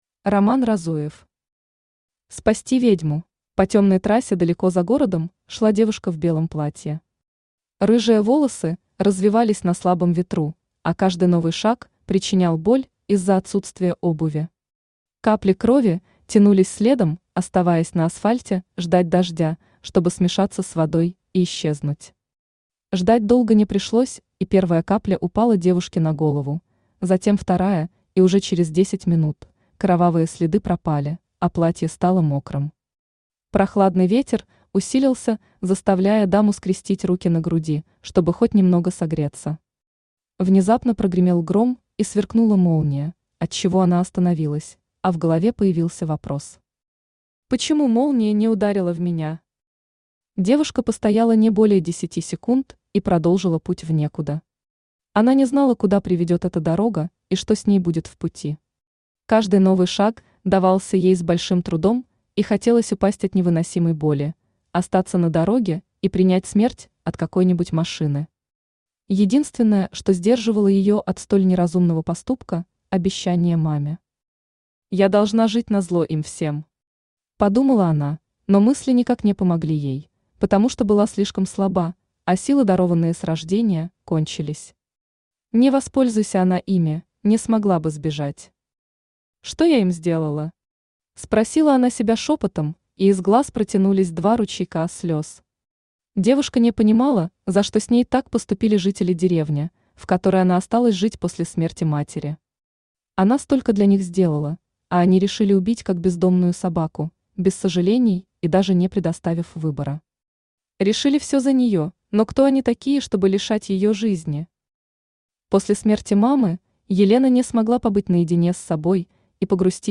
Аудиокнига Спасти ведьму | Библиотека аудиокниг
Aудиокнига Спасти ведьму Автор RoMan Разуев Читает аудиокнигу Авточтец ЛитРес.